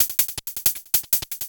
Hats 04.wav